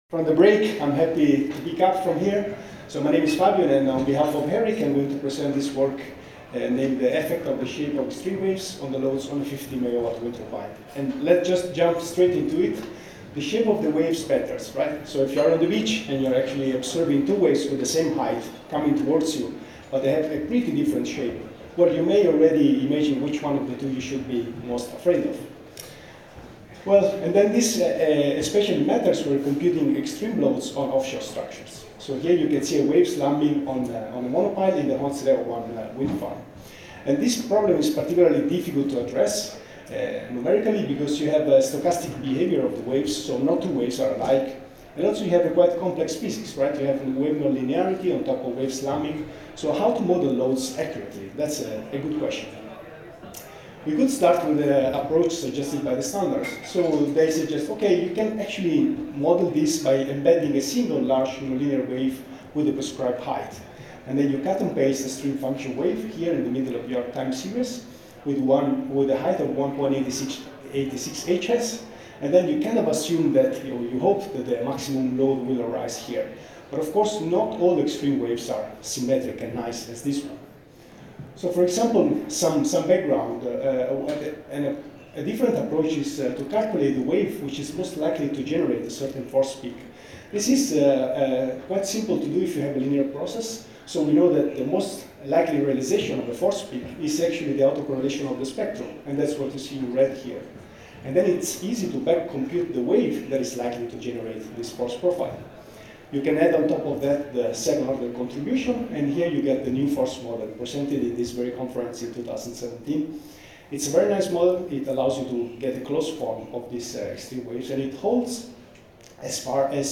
Here you find the presentation and the audio recording from the conference.